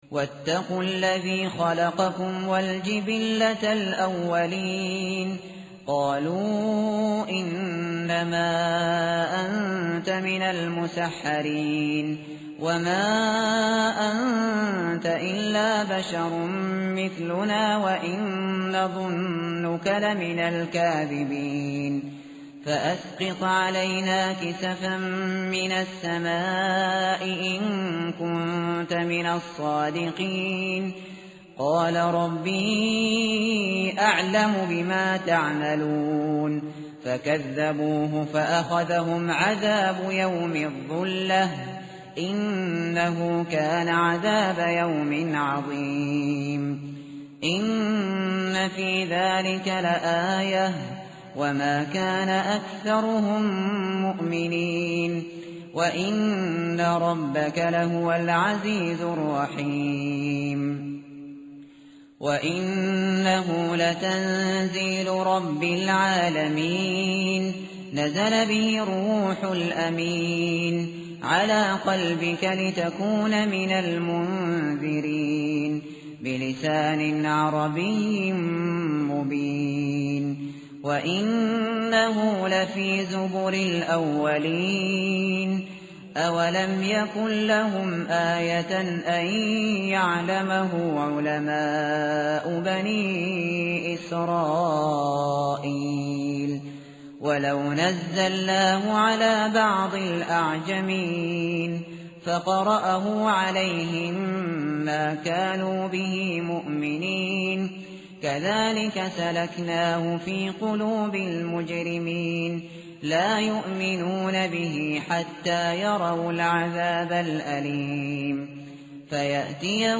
متن قرآن همراه باتلاوت قرآن و ترجمه
tartil_shateri_page_375.mp3